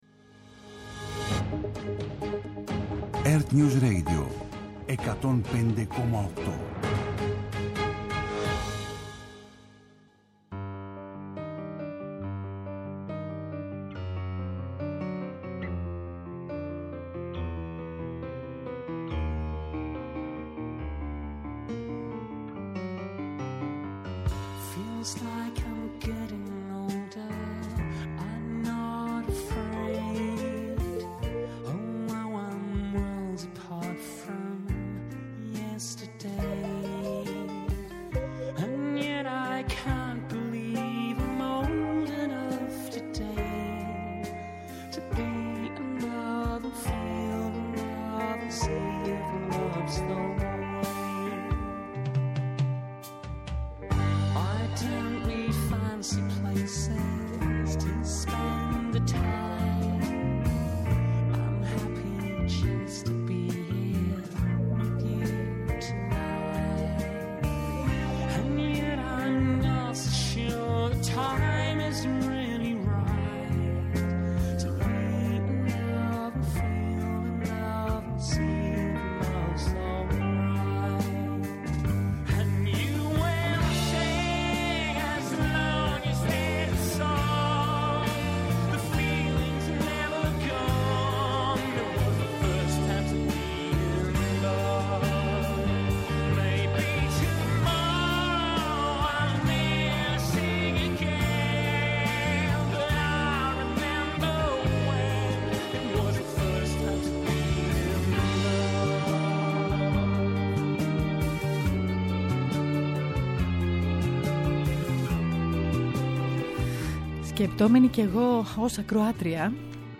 Απόψε καλεσμένος ο Θοδωρής Κολυδάς διευθυντής του εργαστηρίου φυσικών καταστροφών ΔΠΘ, πρώην διευθυντής της ΕΜΥ για την κακοκαιρία.